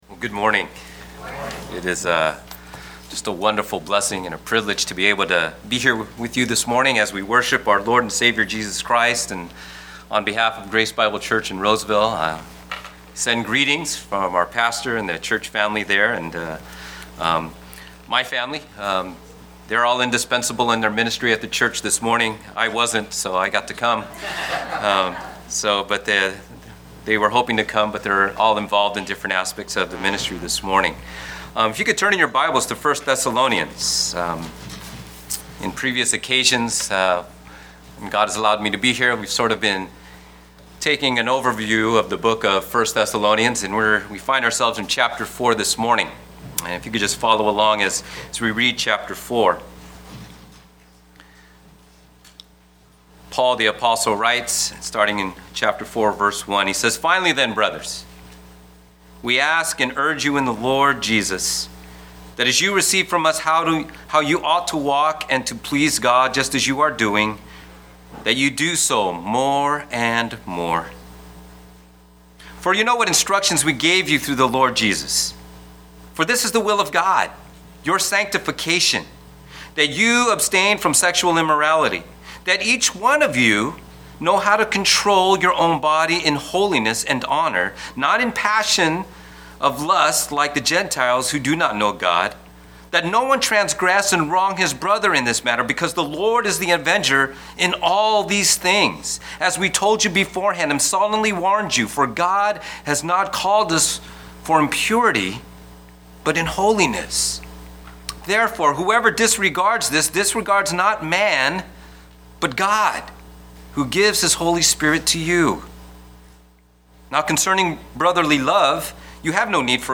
1 Thessalonians 4 Guest Preacher: